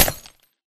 glass3.ogg